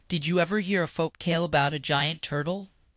On the website, it is more accurate to the voice than my local copy; I'm pretty sure I followed USAGE.md to the letter...